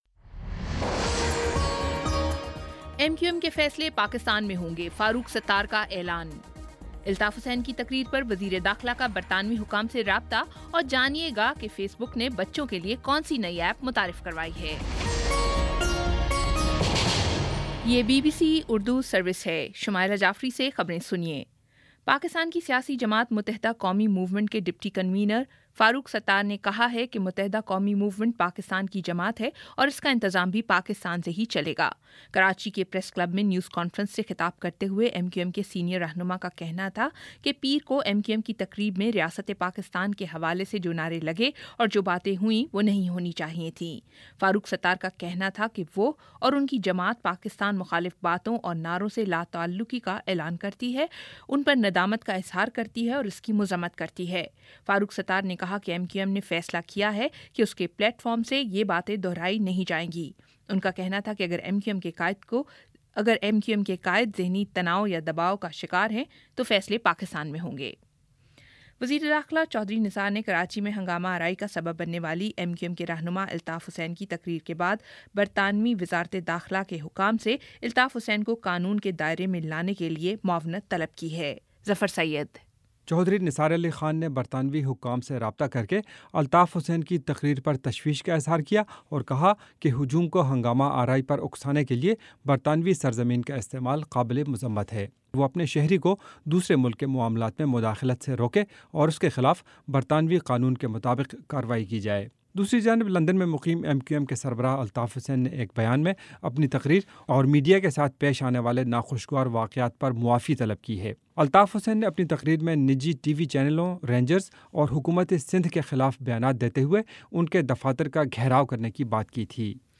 اگست 23 : شام چھ بجے کا نیوز بُلیٹن